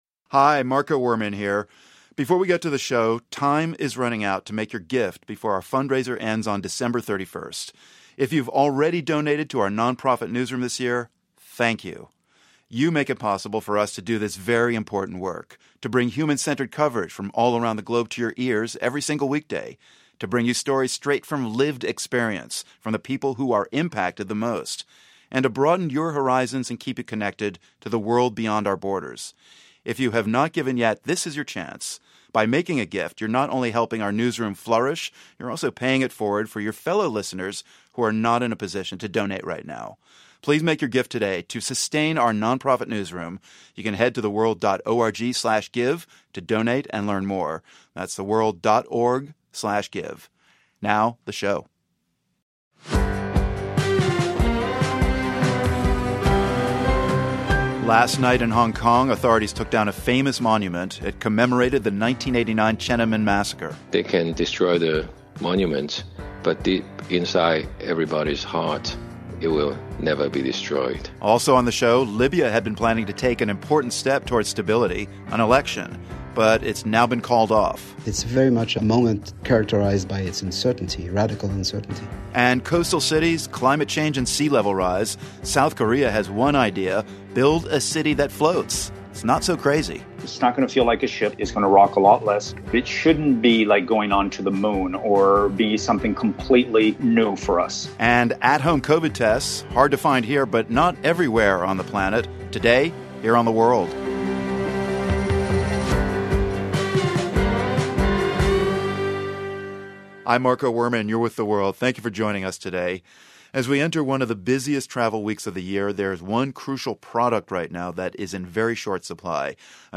As many Americans travel to see loved ones for the holidays, COVID-19 rapid tests are in short supply and often expensive. We hear from a disease expert about how affordable testing options can result in better community health. And the long-awaited election in Libya was set for Dec. 24, but it has been called off, even though the current government’s term is set to expire.